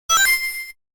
powerUp-4d850a08.mp3